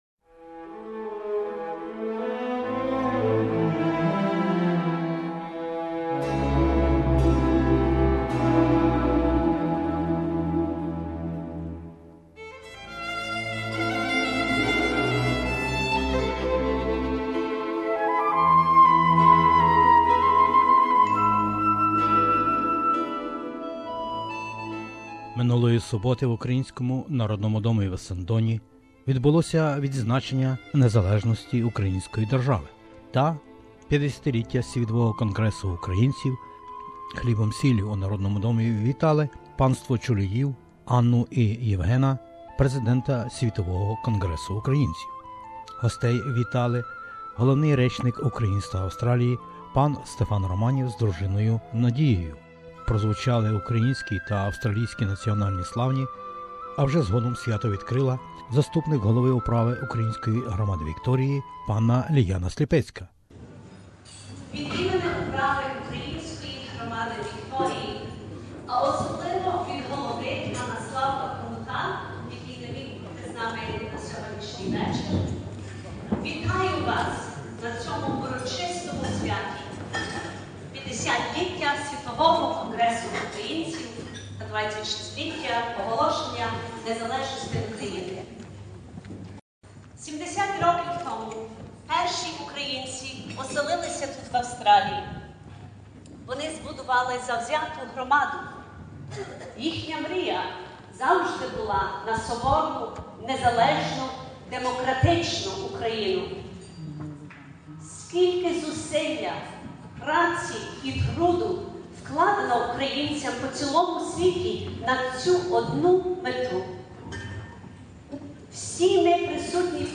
на святковому бенкеті з нагоди 50-річчя СКУ та святкування чергової річниці Незалежності Української держави.